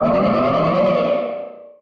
Cri de Méga-Gardevoir dans Pokémon HOME.
Cri_0282_Méga_HOME.ogg